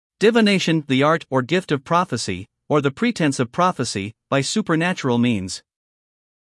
英音/ ˌdɪvɪˈneɪʃn / 美音/ ˌdɪvɪˈneɪʃn /